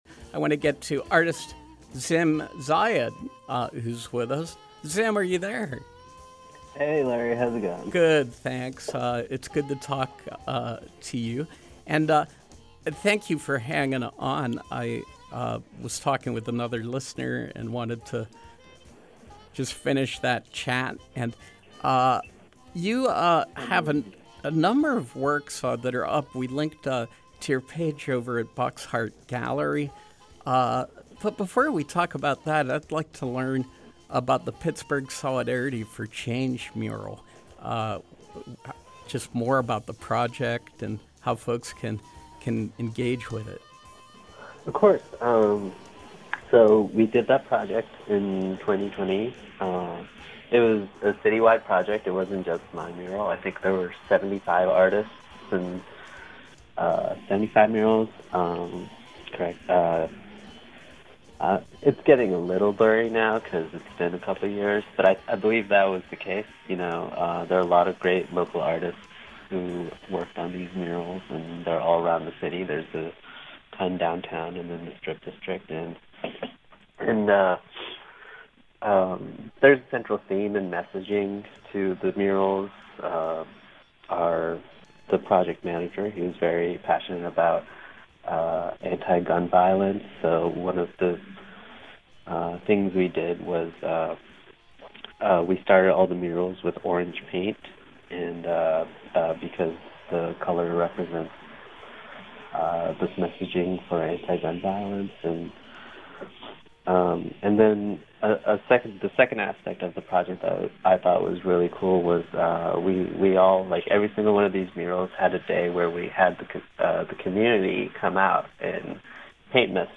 Home » Featured, Interviews